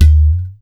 Index of /musicradar/essential-drumkit-samples/Hand Drums Kit
Hand Udu 01.wav